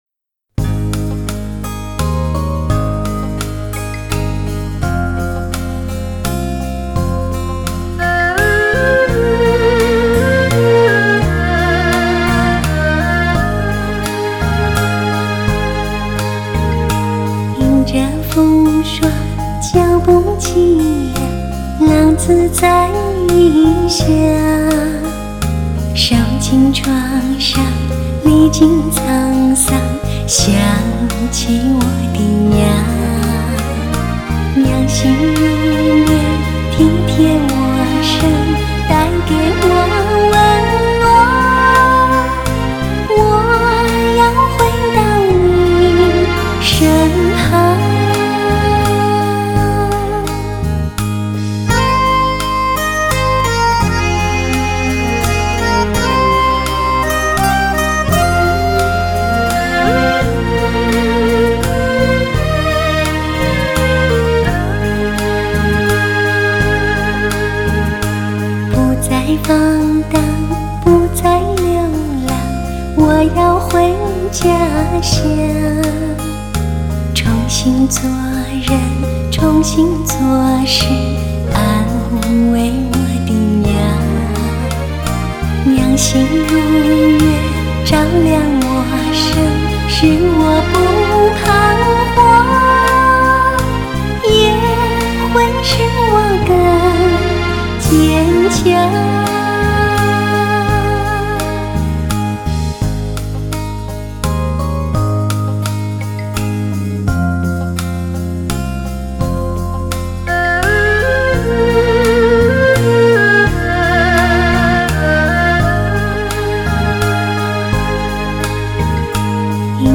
类型: HIFI试音